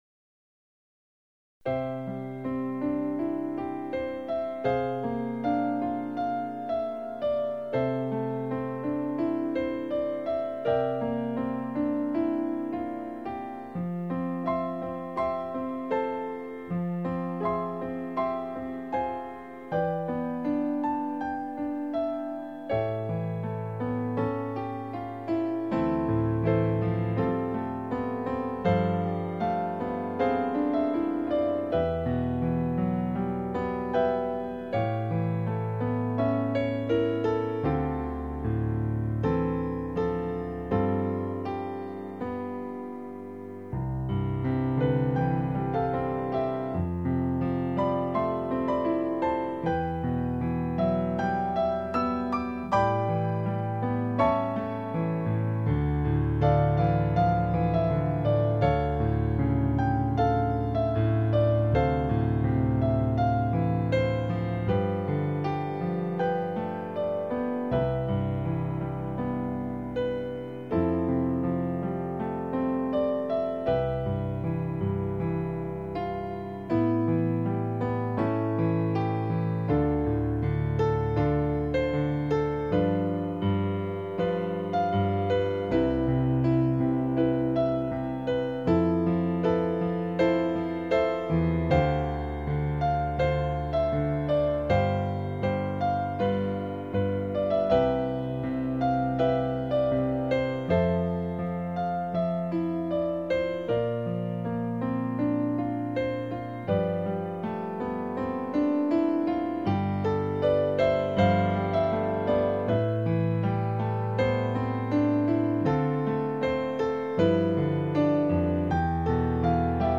Voicing/Instrumentation: Piano Solo
Instrumental Music or New Age